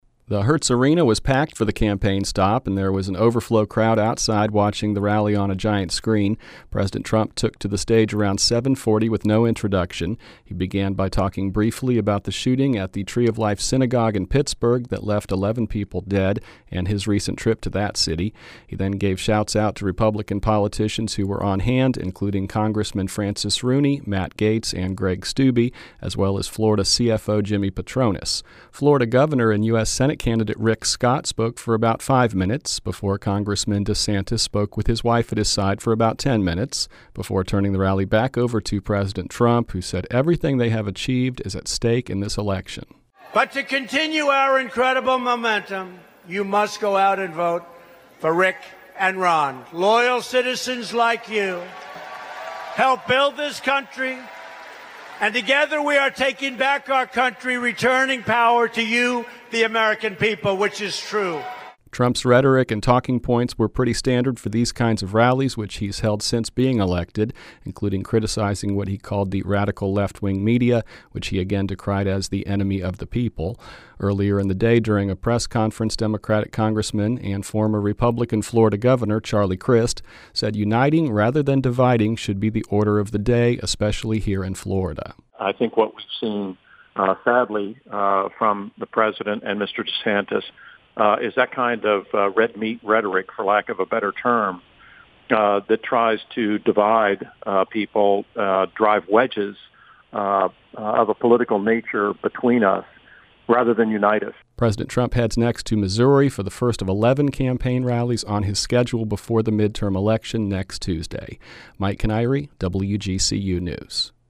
President Donald Trump held a campaign rally at Hertz Arena in Estero on Wednesday, October 31 to  support Governor Rick Scott’s Senate bid, and Congressman Ron DeSantis, who hopes to replace Scott in the governor’s mansion.
The Hertz Arena was packed for the campaign stop, and there was a large overflow crowd outside watching the rally on a giant screen.
Earlier in the day during a press conference Democratic Congressman--and former Republican Governor, Charlie Crist, said uniting, rather than dividing should be the order of the day, especially here in Florida.